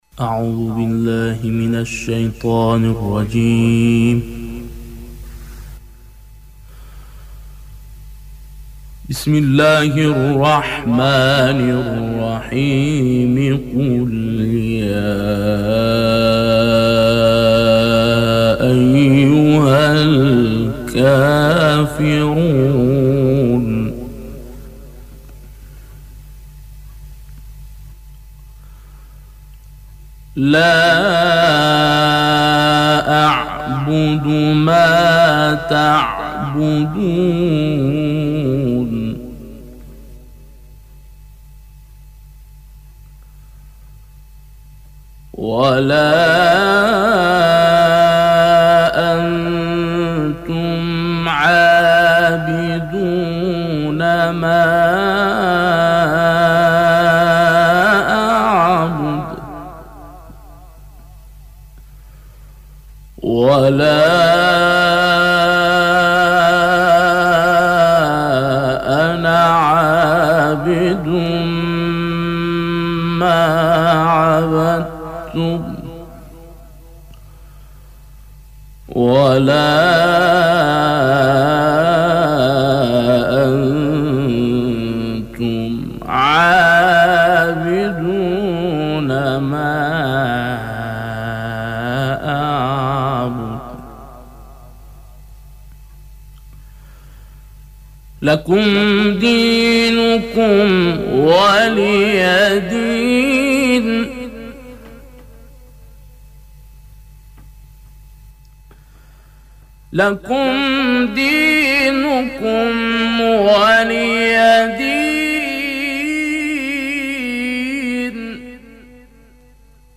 در ادامه صوت این تلاوت به همراه قطعه‌ای تصویری ارائه می‌شود.